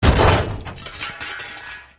Звук, когда автомобиль сдавал назад и въехал в забор или столб (не сильно)